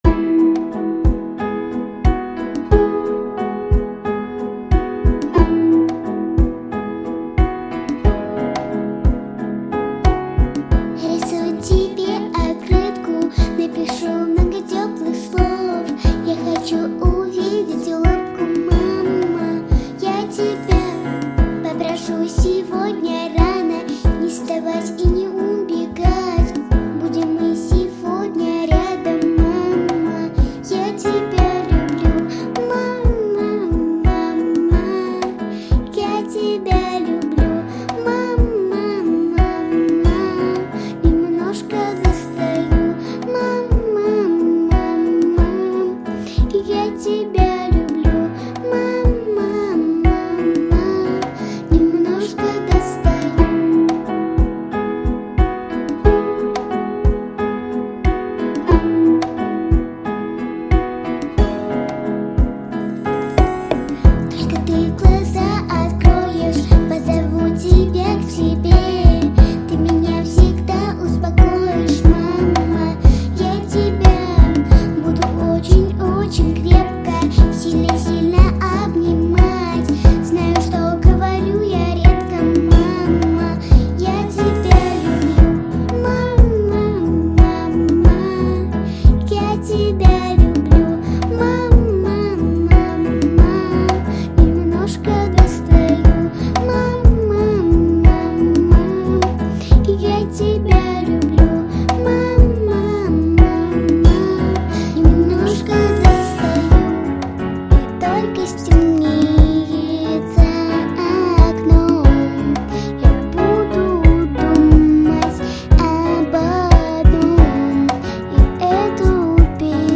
🎶 Детские песни / Песни про маму